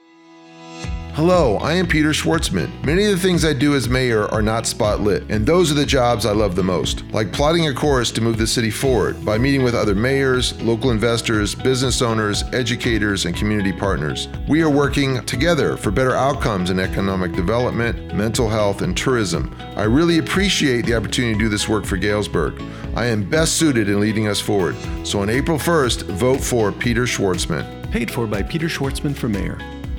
RADIO ADS: